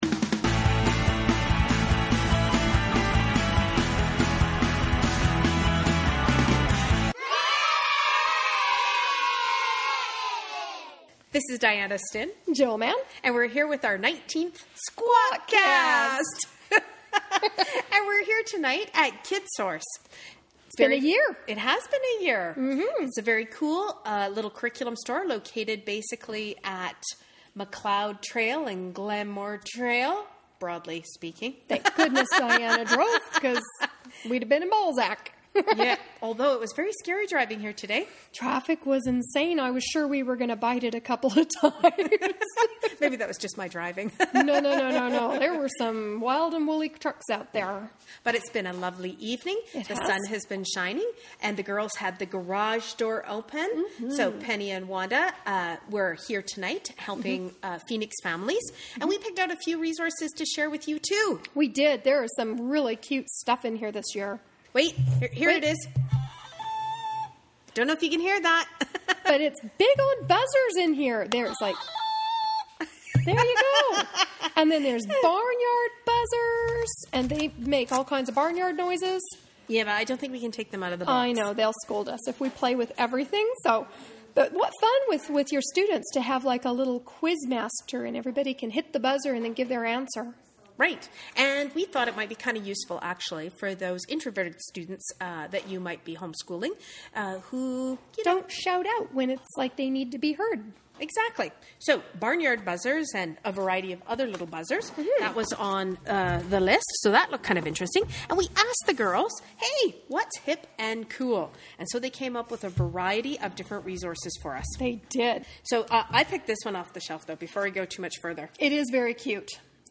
This chat is live from our Wine and Cheese event at Kidsource. Find out what is hip and cool in homeschooling this year.